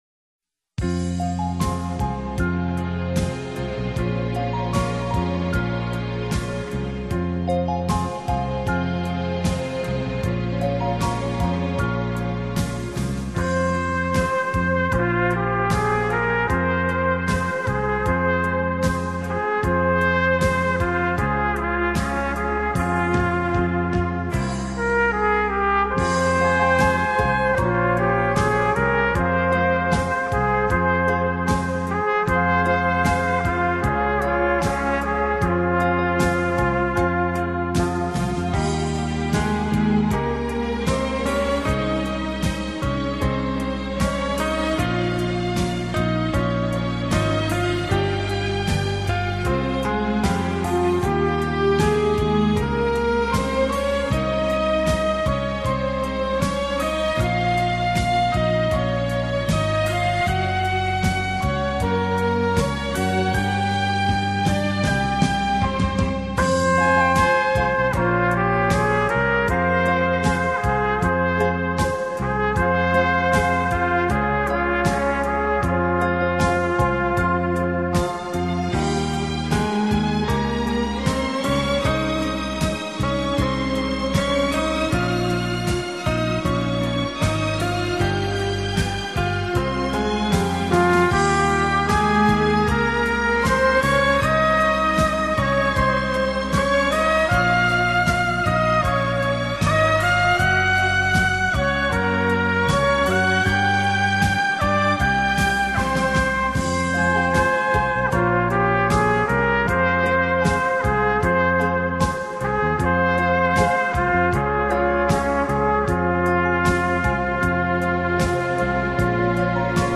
以鲜亮嘹亮、灿烂辉煌而又锐利。
能够在瞬间便演奏出令人感动的跳跃或奔跑的节奏。